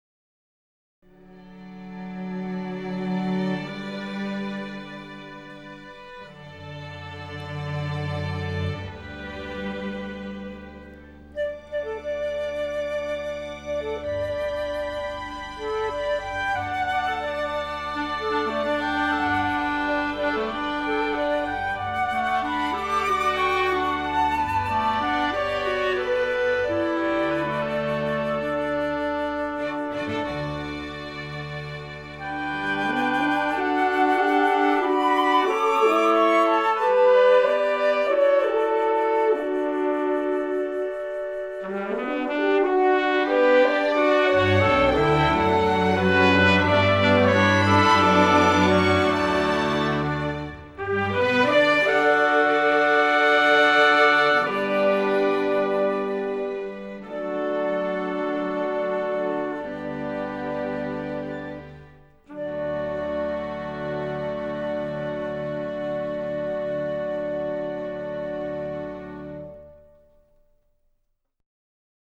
pastoral Americana